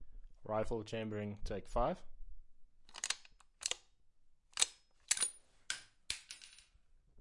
Nunavik " 枪LEE ENFIELD 303步枪开枪射击，弹出弹壳，很干净。
描述：枪李恩恩菲尔德303步枪射击射击弹出套管干净漂亮